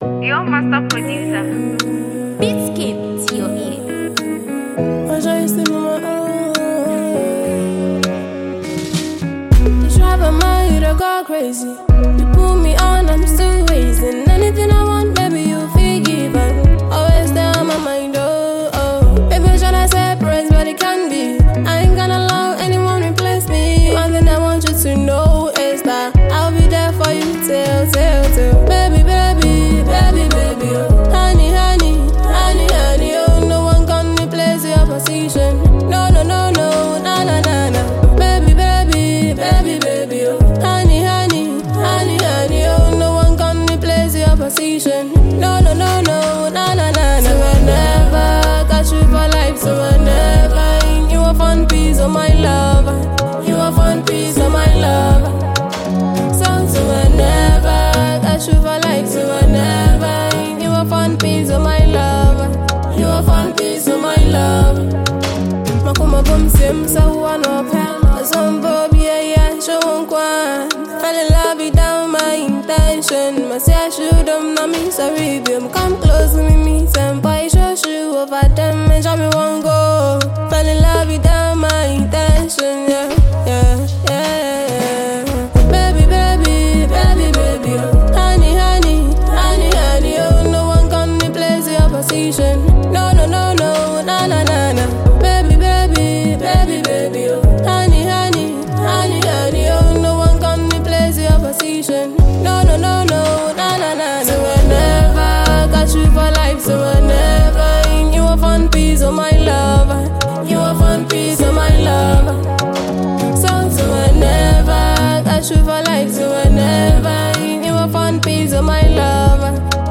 Ghanaian sensational singer